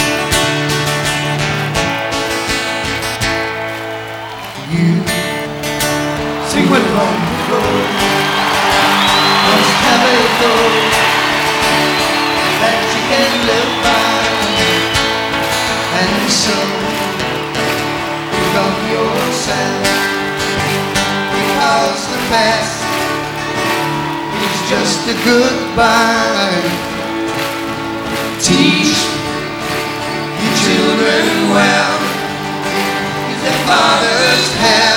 Жанр: Поп музыка / Рок